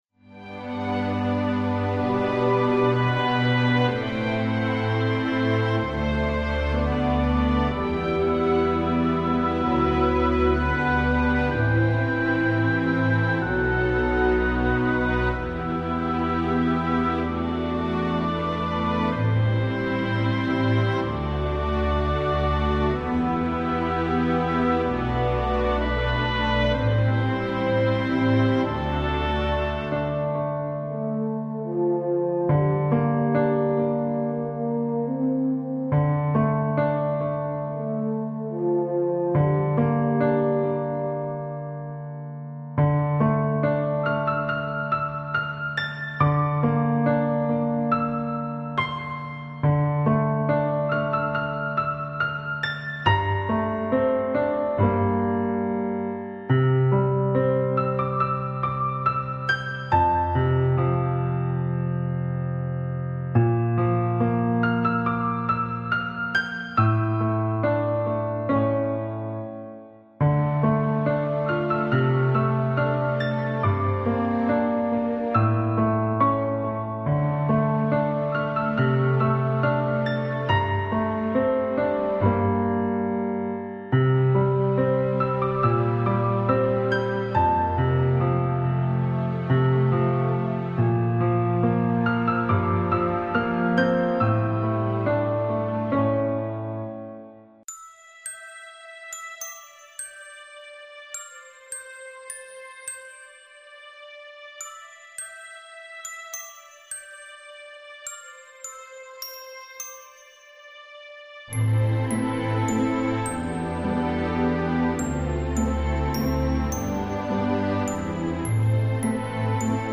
Фоновая музыка